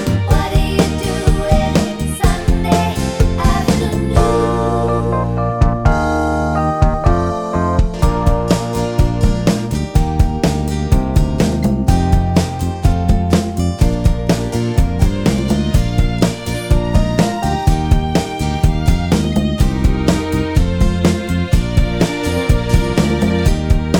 no Backing Vocals Medleys 2:37 Buy £1.50